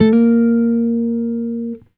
Guitar Slid Octave 11-A#2.wav